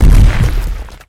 rock_hit1.wav